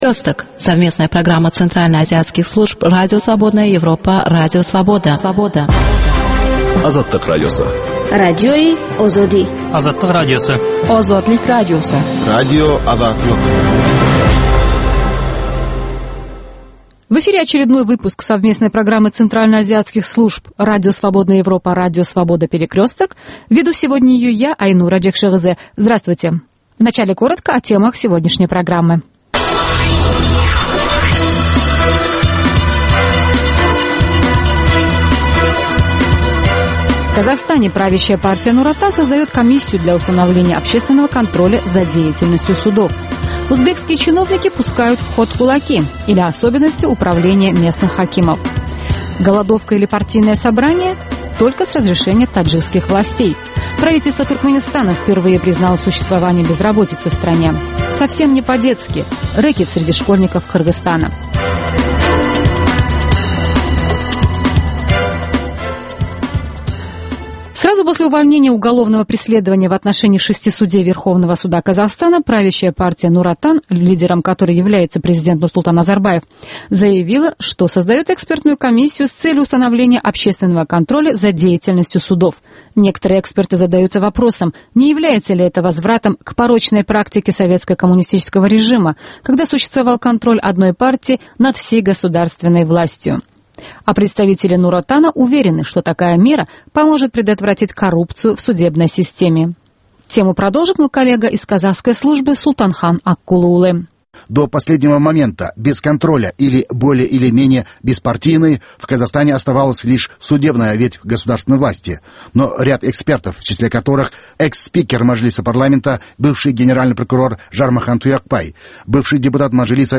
Новости стран Центральной Азии.